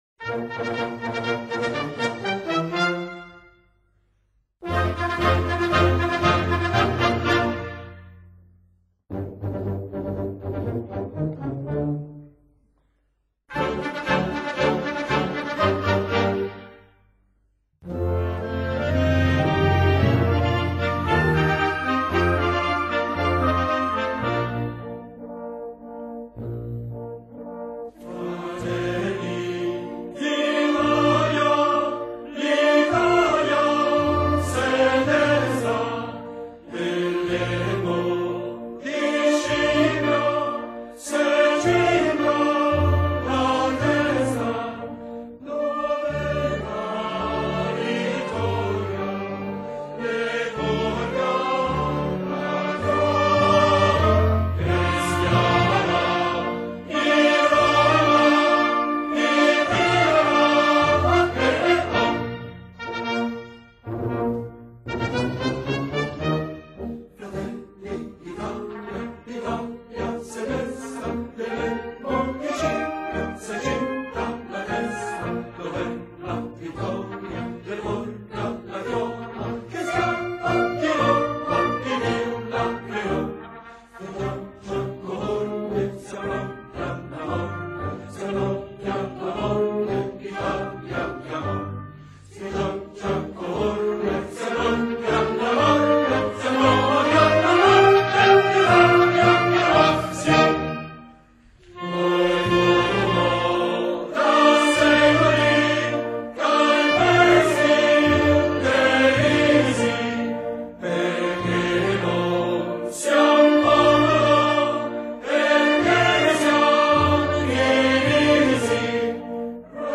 inno3-banda_coro.mp3